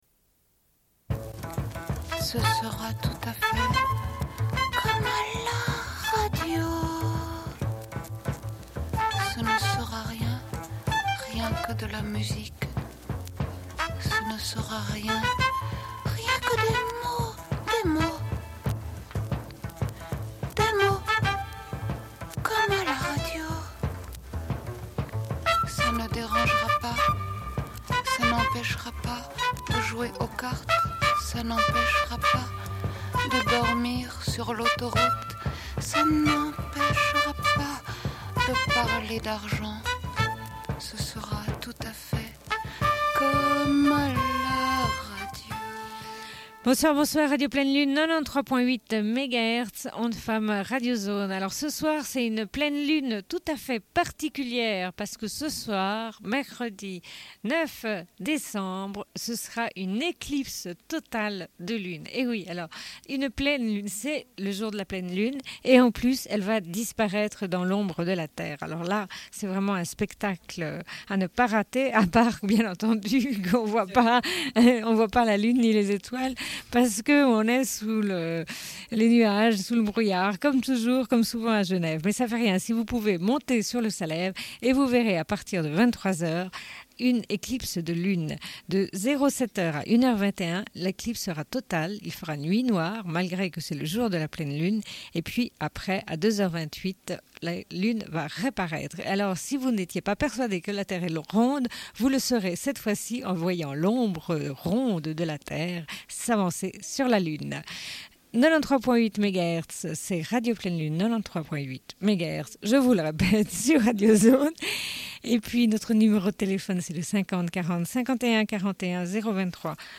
Bulletin d'information de Radio Pleine Lune du 09.12.1992 - Archives contestataires
Une cassette audio, face B29:05